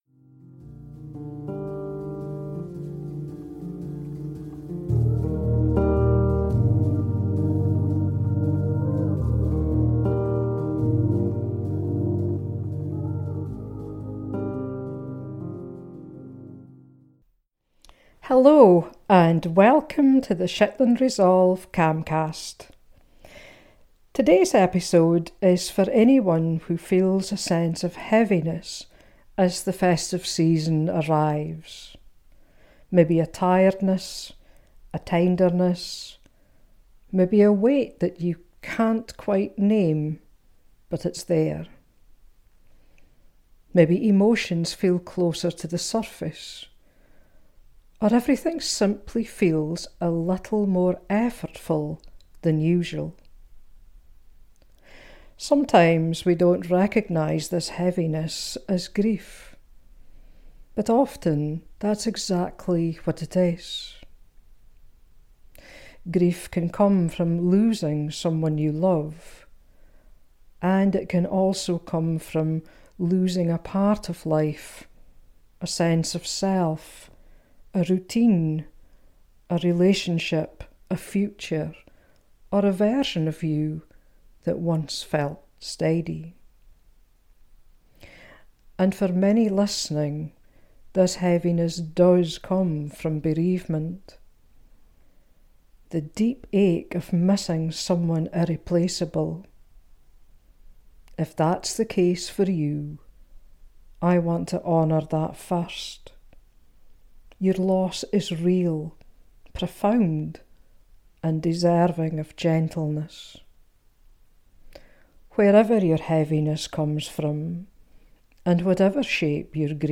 We also explore how the pressure to “be festive” can intensify emotional pain, and how giving yourself permission to step back or soften expectations can bring a sense of steadiness. Toward the end, there’s a short grounding moment, not to change how you feel, but to support your nervous system gently.